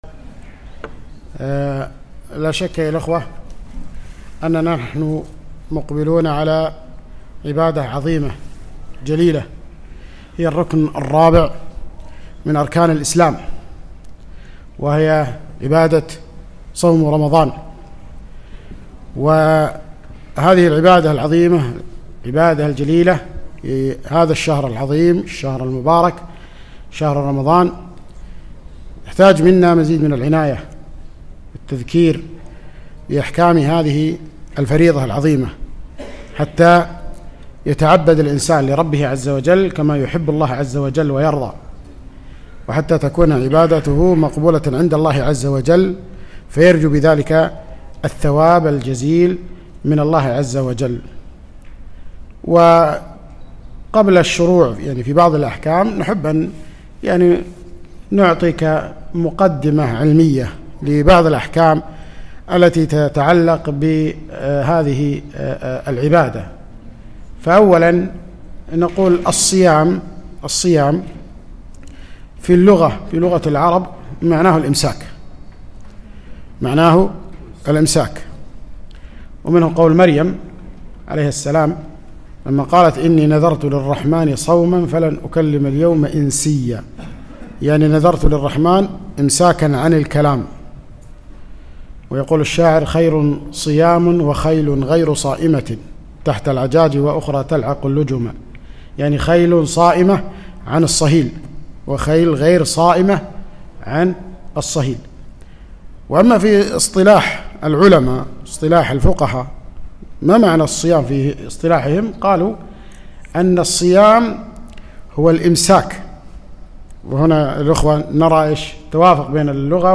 ندوة علمية - (الصيام أحكام وآداب)